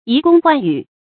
移宫换羽 yí dōng huàn yǔ
移宫换羽发音